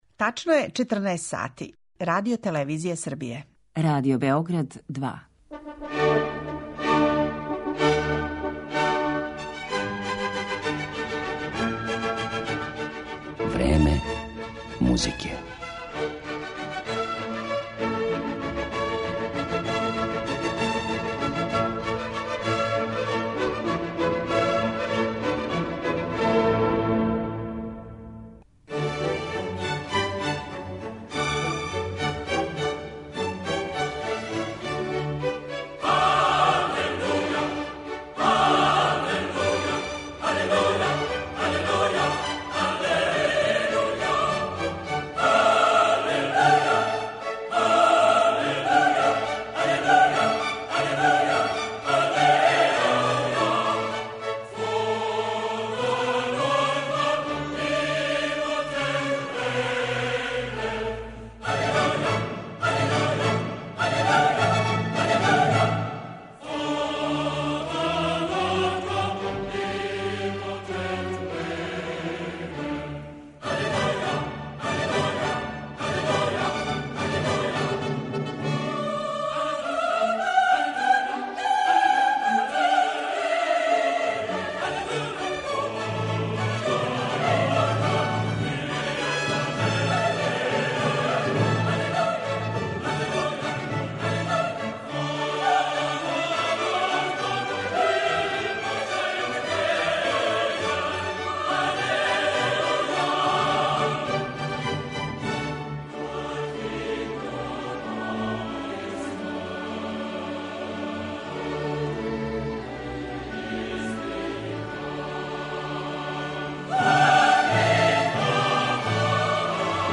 Такође, слушаћете и одабране странице Хендлове музике у интепретацији великих светских солиста, хорова и оркестара.